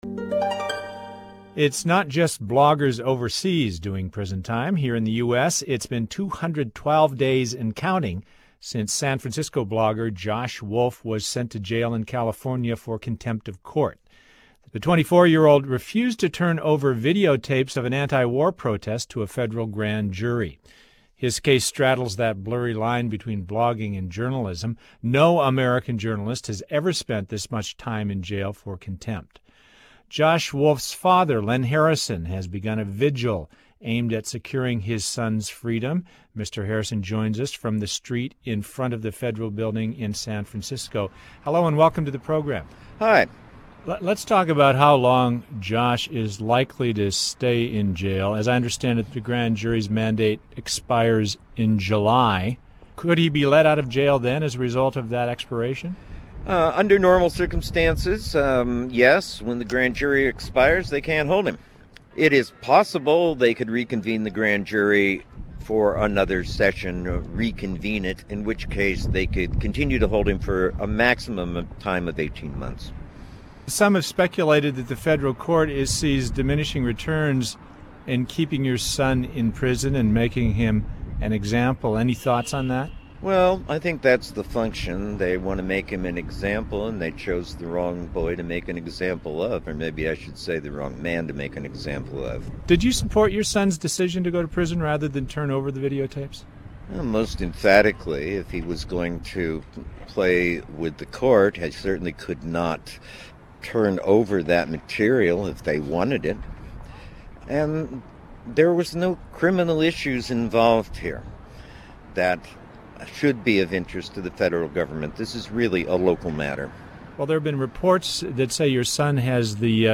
There NPR combines it with their end of the conversation. Result: a "face-to-face" conversation with no telephone.audio.distortion.
In radio parlance, its a "double-ender."
§MP3 version of the 3.5 minute interview